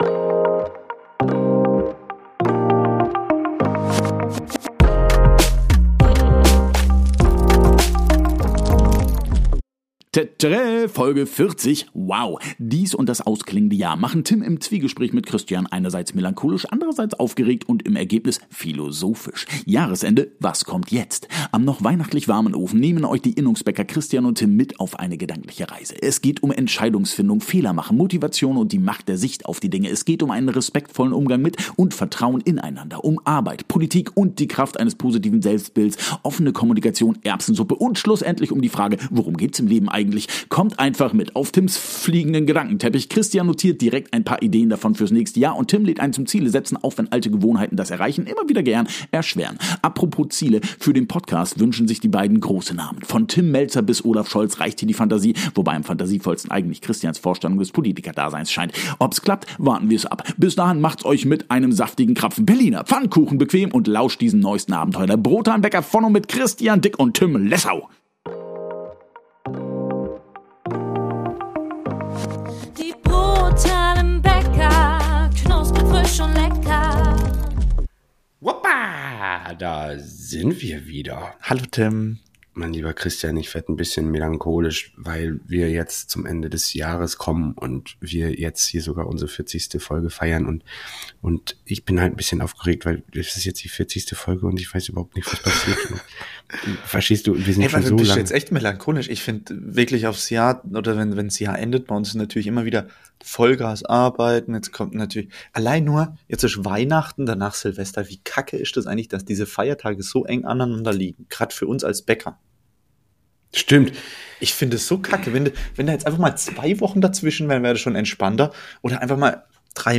Zwiegespräch